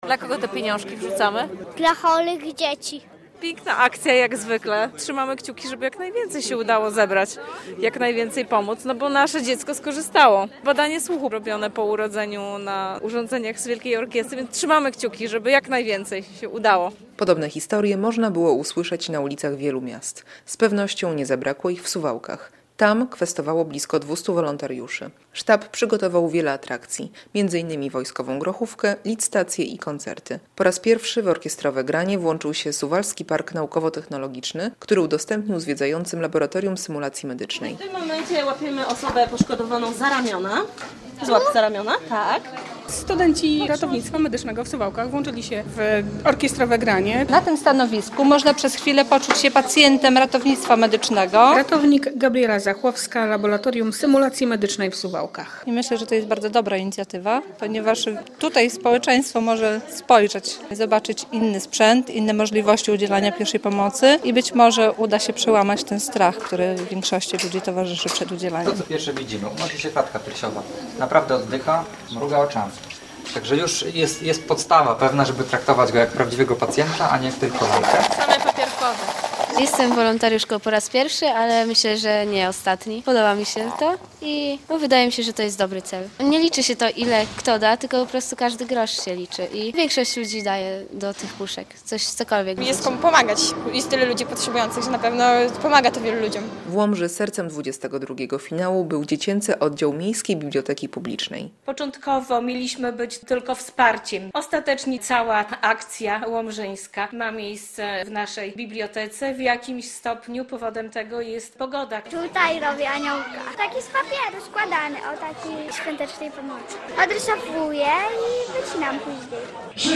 Wielka Orkiestra Świątecznej Pomocy w Podlaskiem - relacja